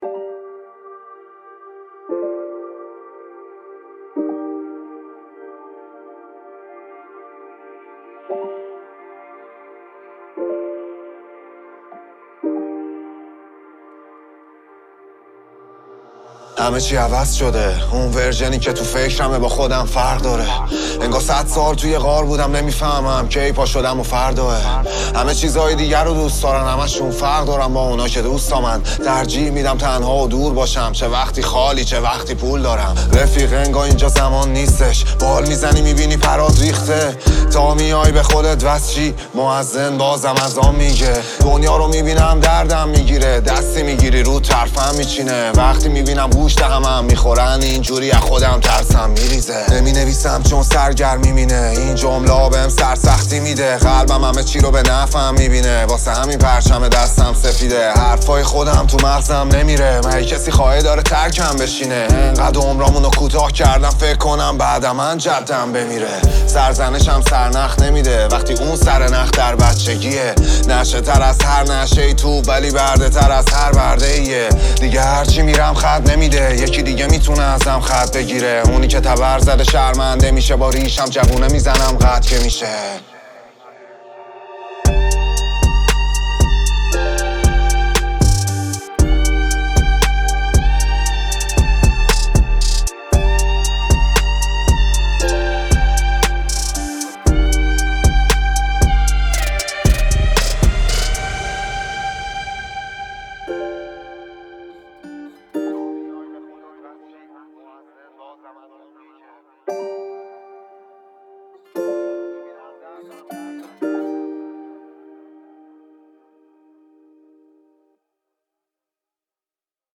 دانلود آهنگ رپ فارسی